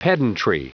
Prononciation du mot pedantry en anglais (fichier audio)
Prononciation du mot : pedantry